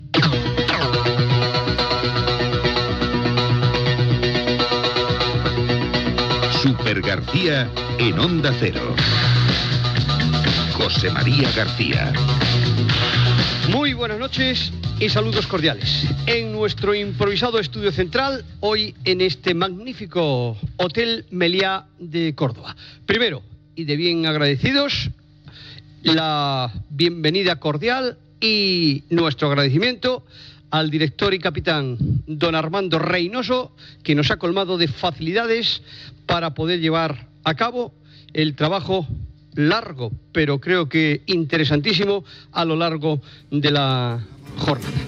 Careta, salutació i agraïments des d'un hotel de Còrdova, ciutat on ha acabat l'etapa de la Vuelta Ciclista a España.
Esportiu